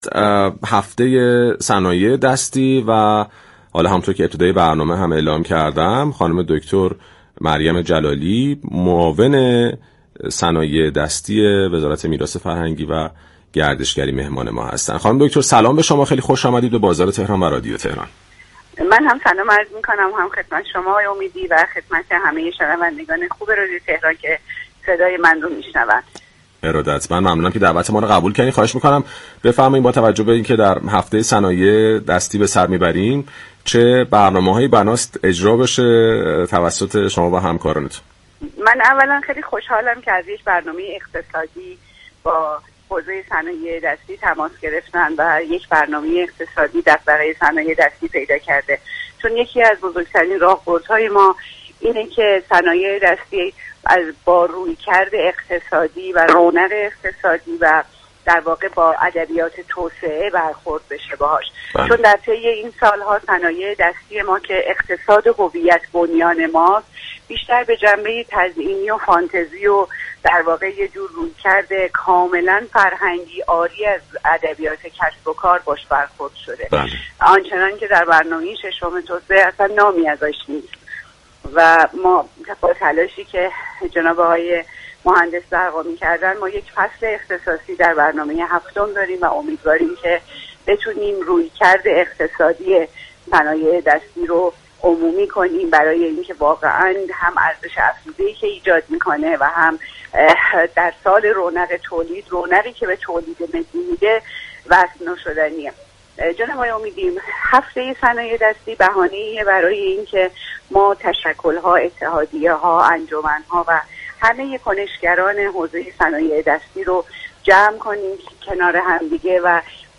به گزارش پایگاه اطلاع رسانی رادیو تهران، مریم جلالی معاون صنایع دستی سازمان میراث فرهنگی و گردشگری در گفت و گو با «بازار تهران» اظهار داشت: متاسفانه طی این سال‌ها صنایع دستی كه اقتصاد هویت‌بنیان ما است بیشتر به جنبه تزئینی و فانتزی و رویكرد كاملا فرهنگی و عاری از ادبیات كسب و كار با آن برخورد شده است آن چنان كه در برنامه ششم توسعه نامی از صنایع دستی برده نشده است.